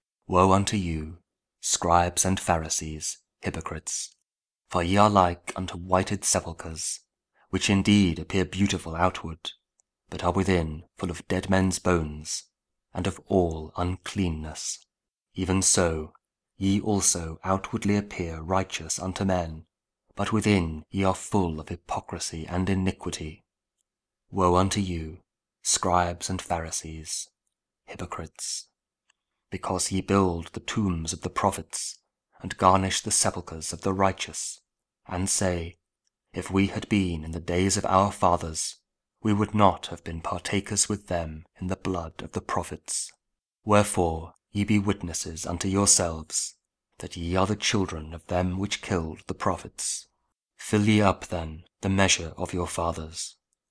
Matthew 23: 27-32 – Week 21 Ordinary Time, Wednesday (Audio Bible KJV, Spoken Word)